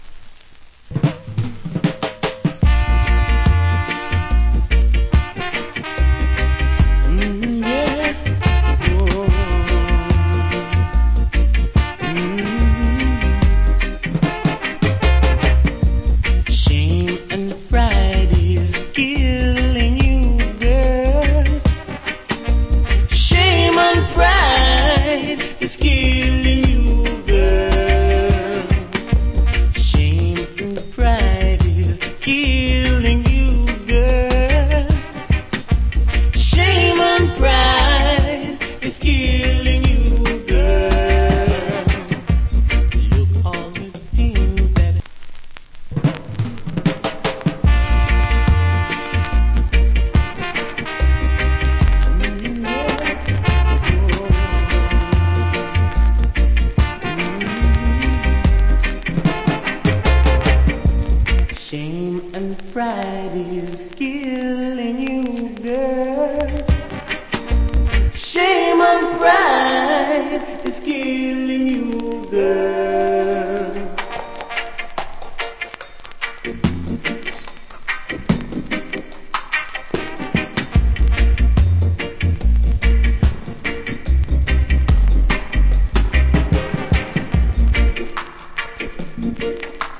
Good Roots Rock Vocal.